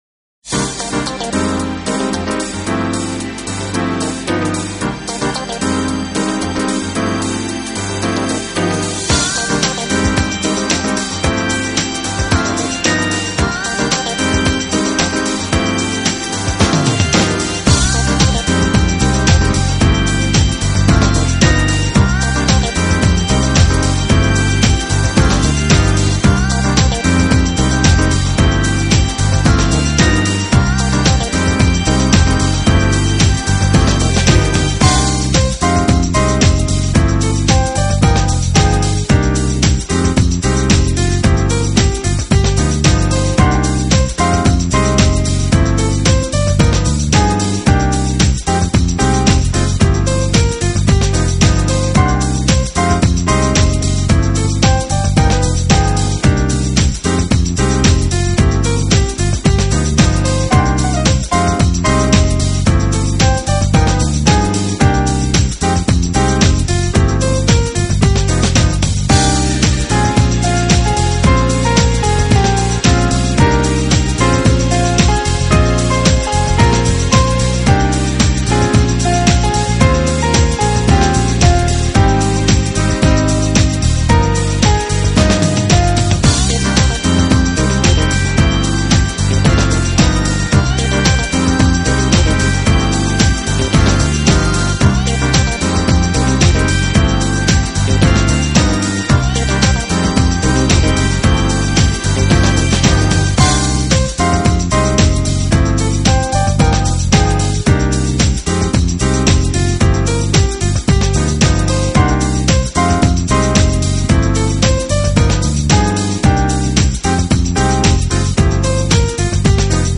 Contemporary Jazz / New Age